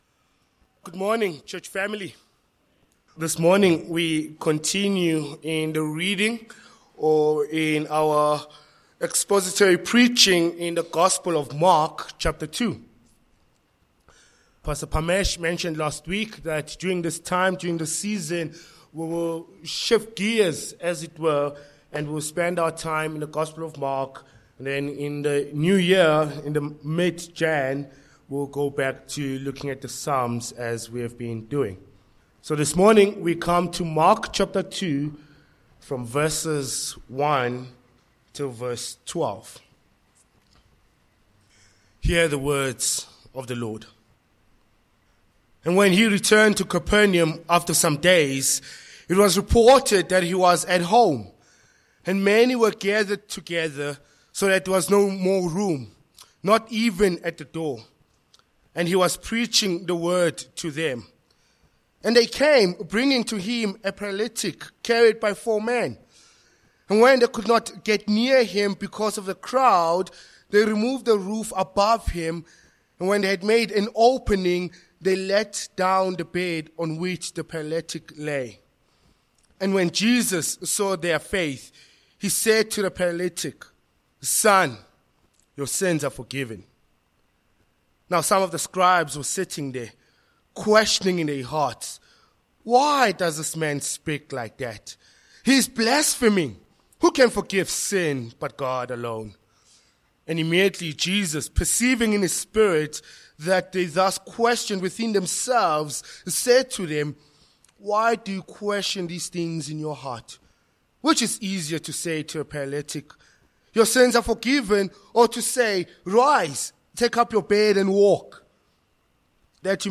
Mark 2:1-12 Service Type: Morning Passage